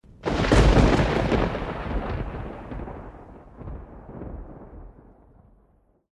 Звуки молнии
На этой странице собраны разнообразные звуки молнии и грома – от резких электрических разрядов до глубоких раскатов после удара.
Гром летней молнии